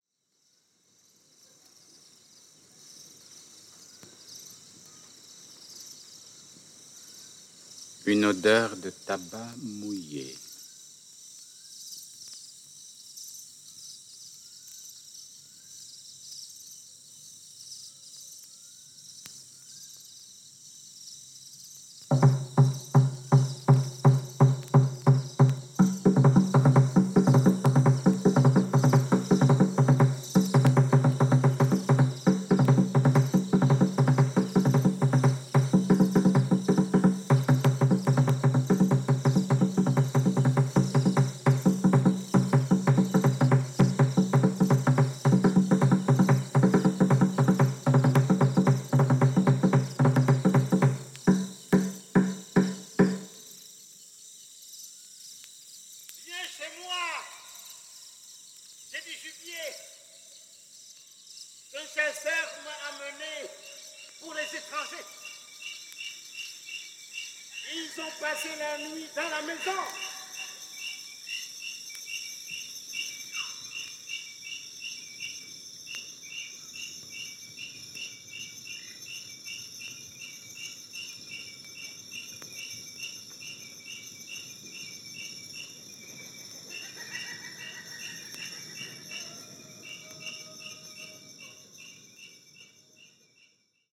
中央アフリカ・カメルーンの情景を収めた